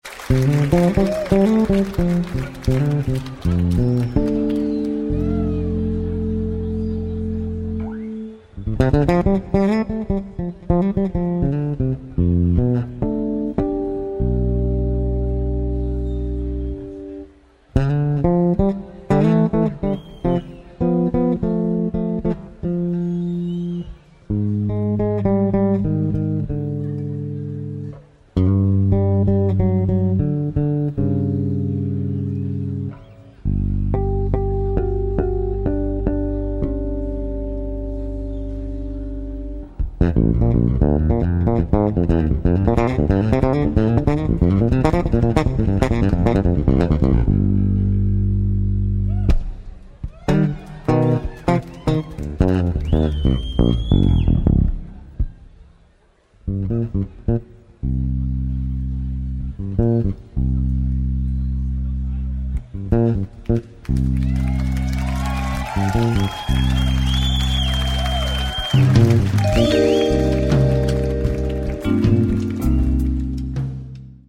4-snarige jazz bas